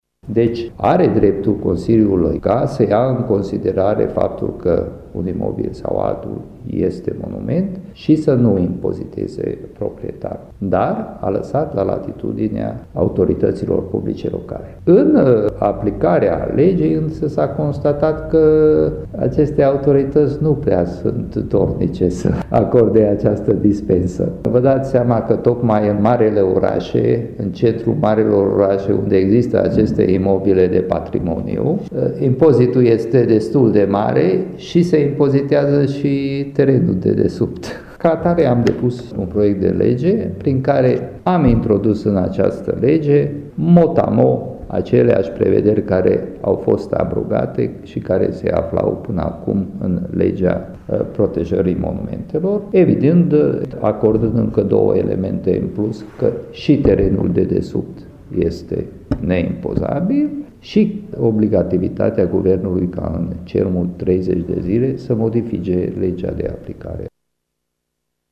Deputatul UDMR Covasna, Marton Arpad, a declarat vineri, într-o conferinţă de presă, că noul Cod Fiscal a abrogat, practic, această prevedere existentă în Legea monumentelor, lăsând la latitudinea autorităţilor locale acordarea dispenselor.